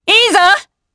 Demia-Vox_Happy4_jp.wav